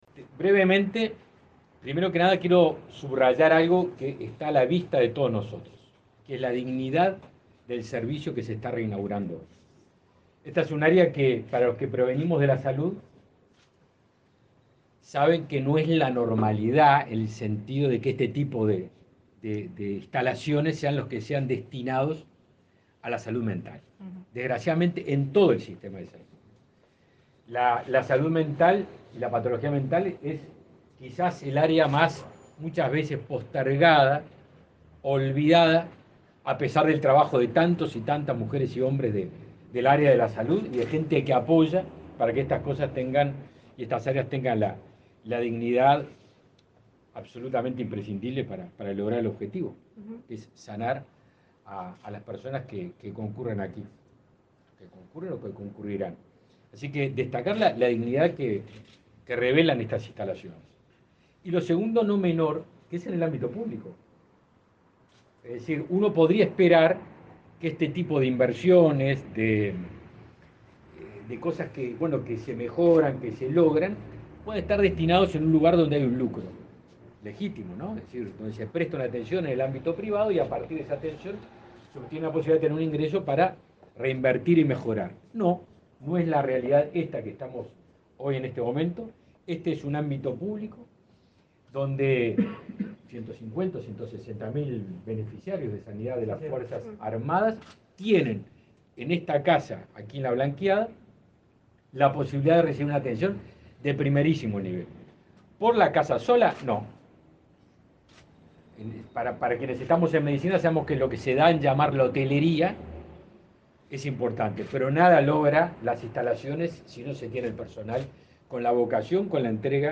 Palabras del ministro de Defensa Nacional, Javier García
El ministro de Defensa Nacional, Javier García, participó, este martes 24, de la inauguración de la sala de internación de psiquiatría del Hospital